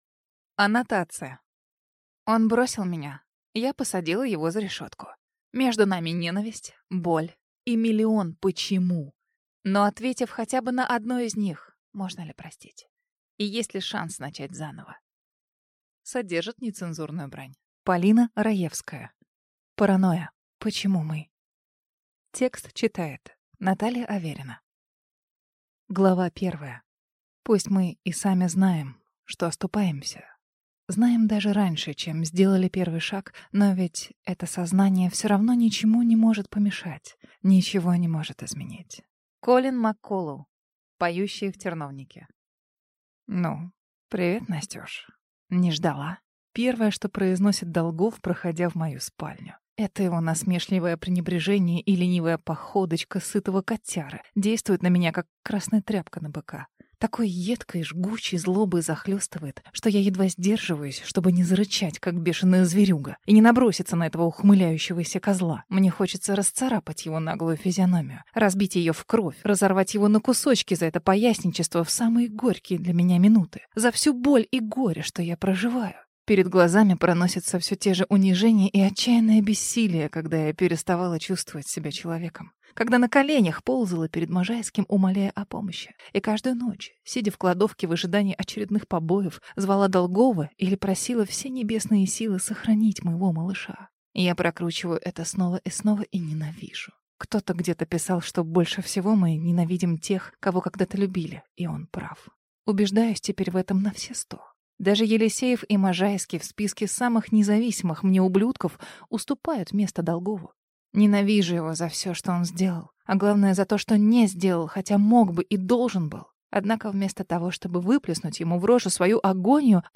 Аудиокнига Паранойя. Почему мы?